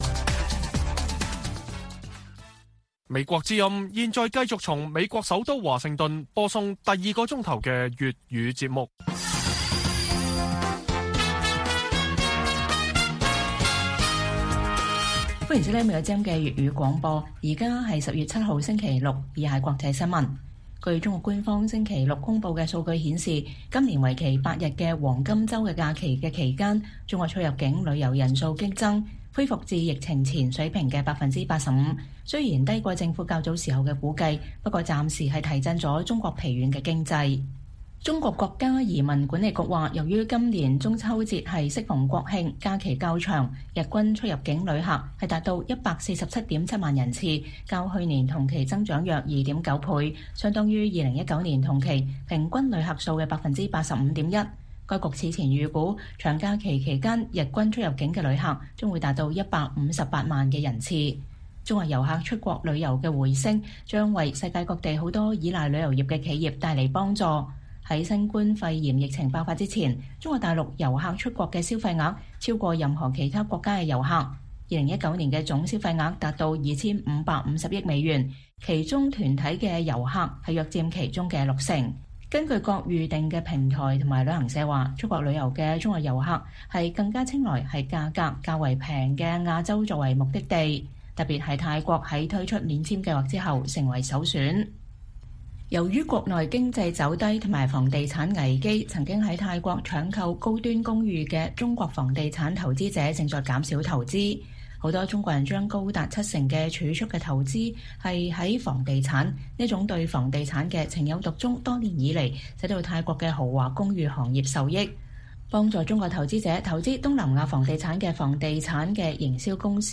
粵語新聞 晚上10-11點: 中國黃金週期間出入境人數恢復近疫情前水平